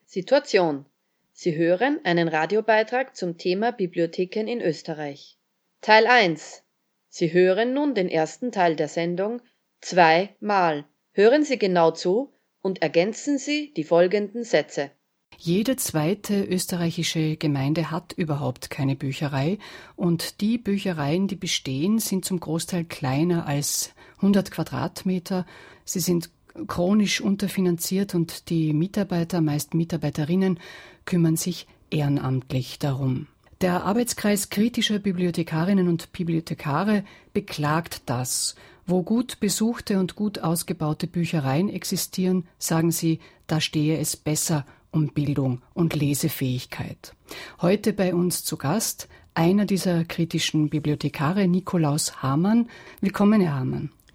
B2.1-Hoerbeitrag-Bibliotheken-Teil-1-mit-Intro.mp3